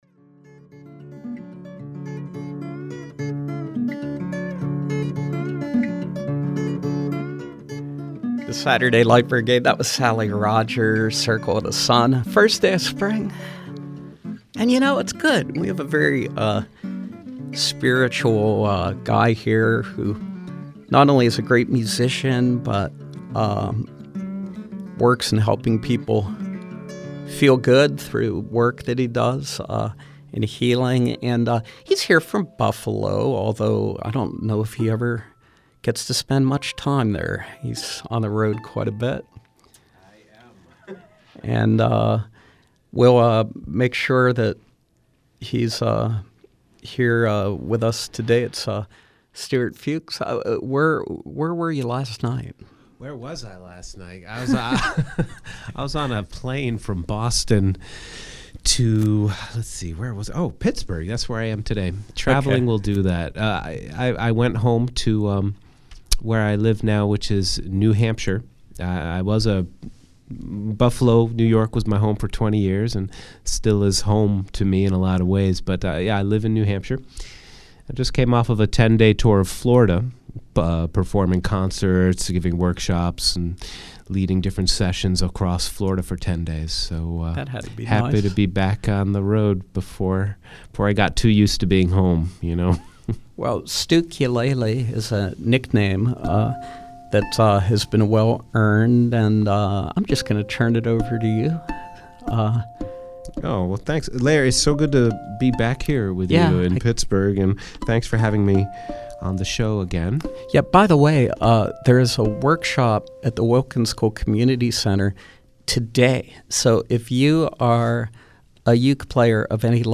ukulele
performing live.